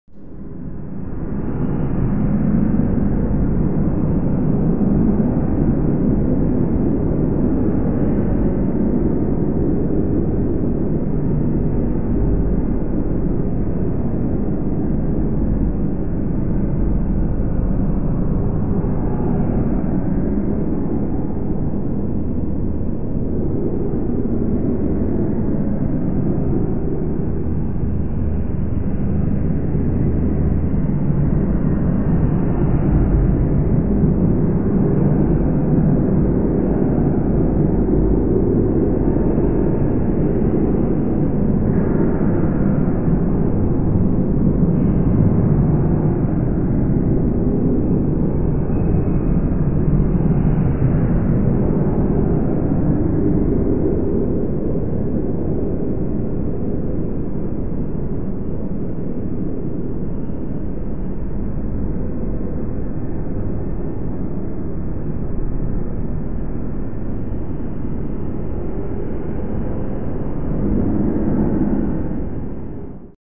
Звуки тумана
Здесь собраны записи, передающие мягкое дыхание туманного леса, шелест капель на паутине, приглушенные голоса природы.
Ощущение жуткого тумана